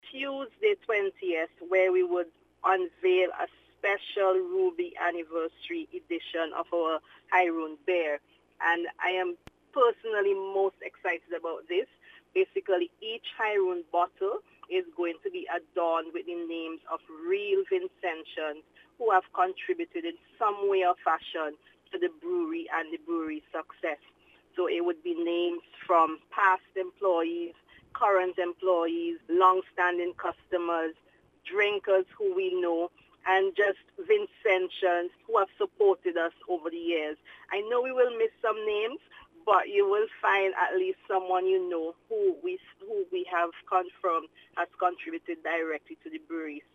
In an interview with NBC News